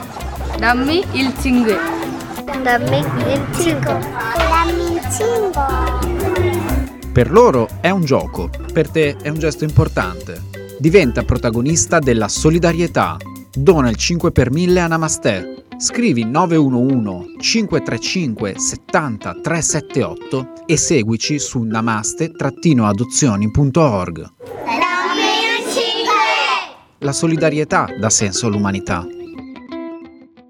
SPOT RADIO CITTÀ FUJIKO 2020:
spot-namaste-2020.mp3